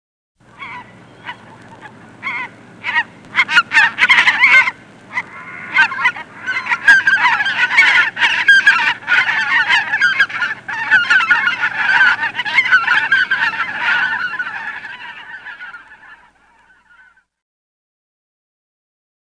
oie | Université populaire de la biosphère
Elle cacarde, glousse et siffle
oies_2.mp3